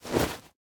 Minecraft Version Minecraft Version snapshot Latest Release | Latest Snapshot snapshot / assets / minecraft / sounds / item / bundle / drop_contents2.ogg Compare With Compare With Latest Release | Latest Snapshot
drop_contents2.ogg